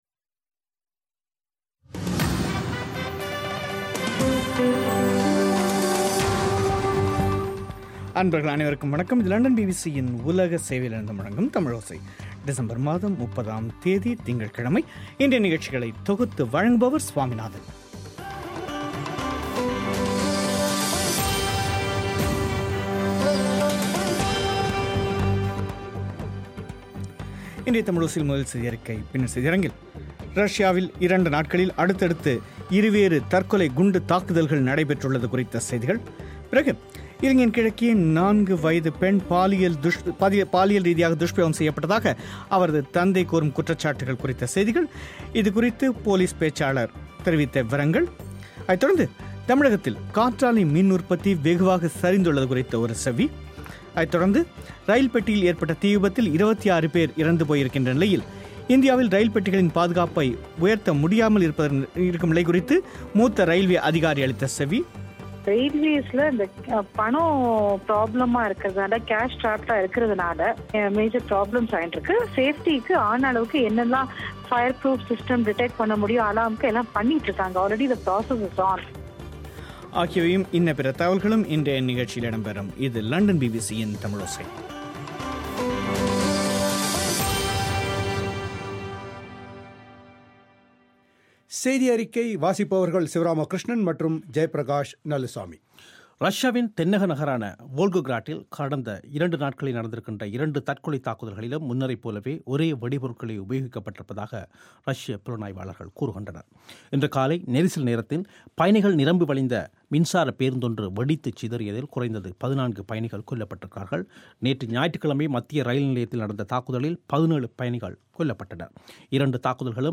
தமிழோசை செய்தியறிக்கை டிசம்பர் 30